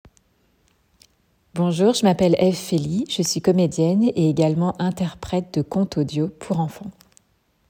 Voix off
25 - 35 ans